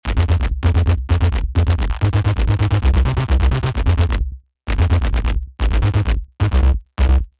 An absolute classic throwback to the early, early electro sound from the 2000s
Runner_130 – Stack_Bass_Main_1
Runner_-2-Runner_130-Stack_Bass_Main_1.mp3